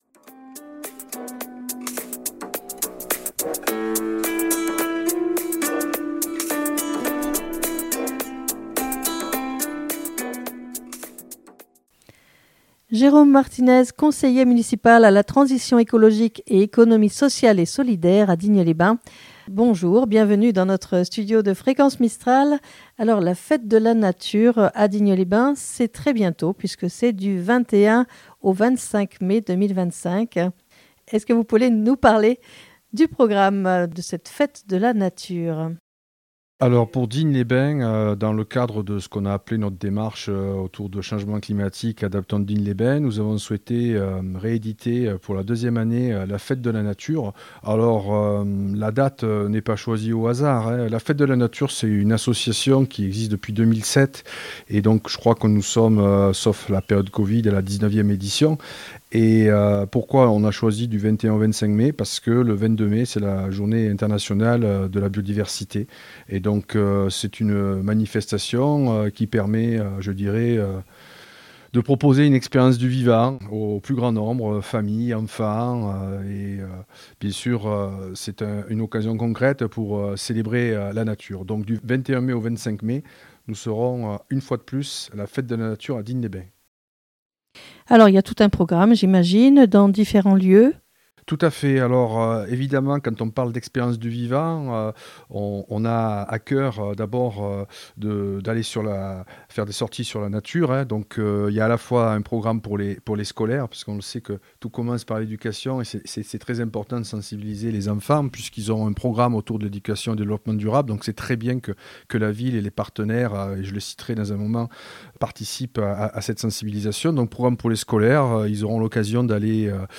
Jérôme Martinez Conseiller municipal à la transition écologique et à l'économie sociale et solidaire à Digne les Bains présente le programme